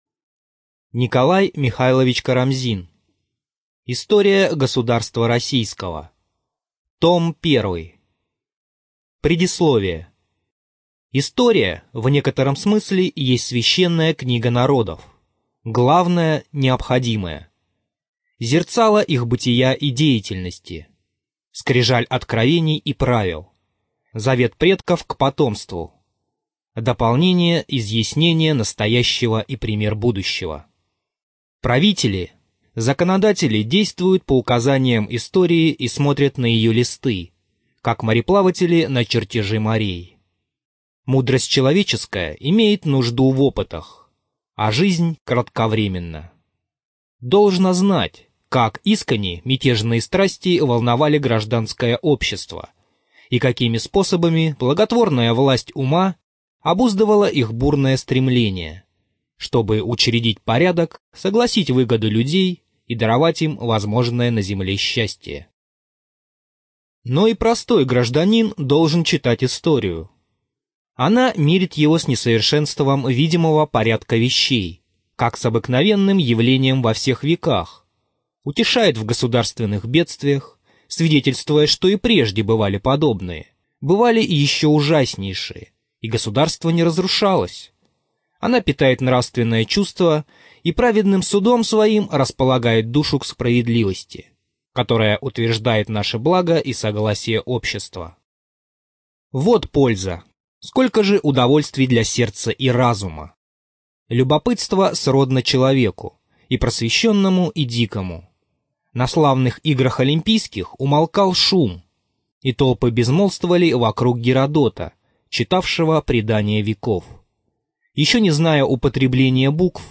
ЖанрИсторическая проза, Наука и образование